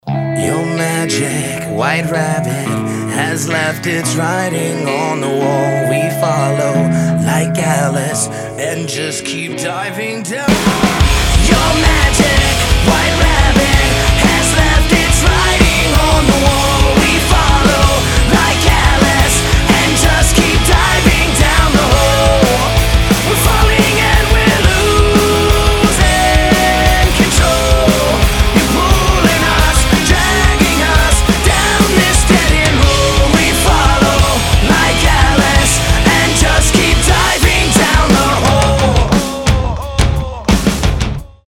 • Качество: 320, Stereo
громкие
nu metal
Hard rock
post-grunge
Alt Metal